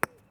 sound / sfx / ball / concrete3.wav
concrete3.wav